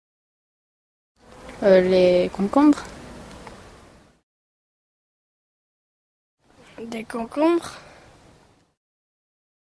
uitspraak Les concombres uitspraak Des concombres